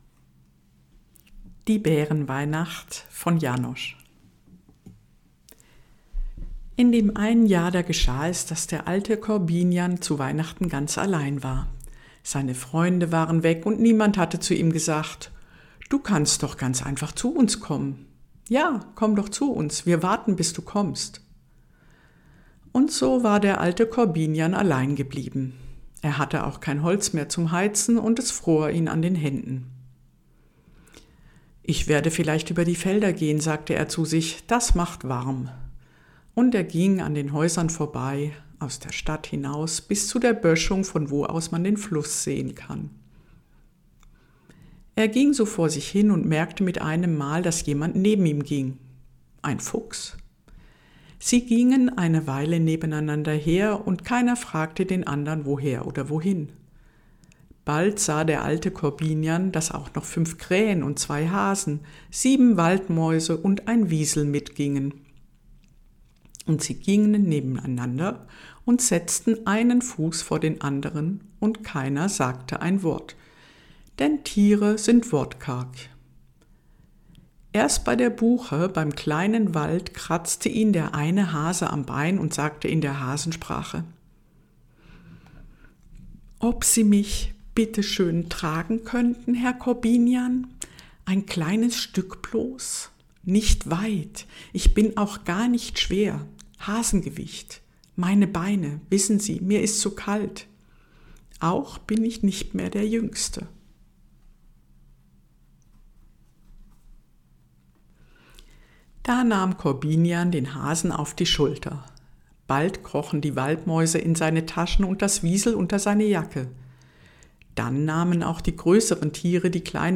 Ich lese Dir die Bärenweihnachtsgeschichte von Janosch vor.